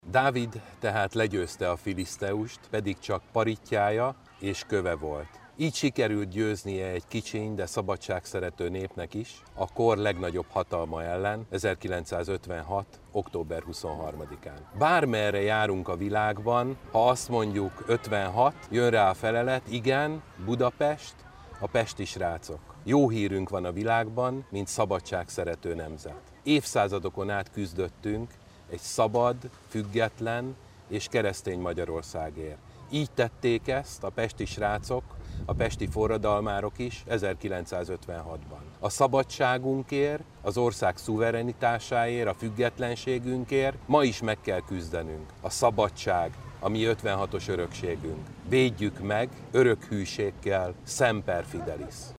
Pánczél Károly országgyűlési képviselőt hallják: